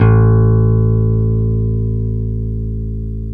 Index of /90_sSampleCDs/Roland L-CD701/BS _E.Bass 5/BS _Dark Basses